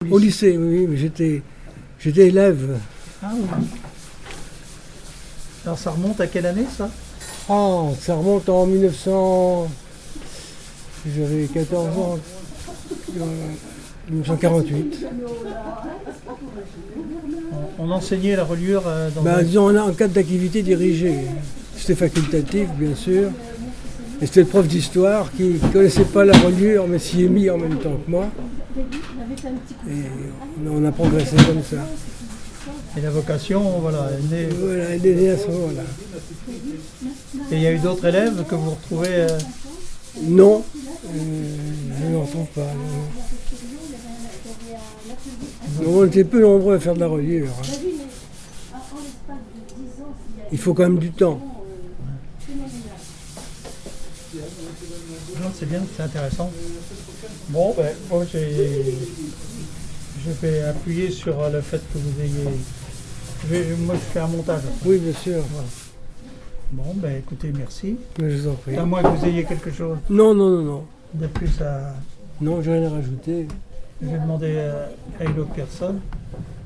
conversation avec des membres de l'association Art et Reliure de La Roche-sur-Yon pour l'émission Comme toujours, de TV Vendée
Témoignage